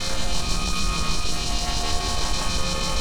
snd_axis_geno_laser_drill.wav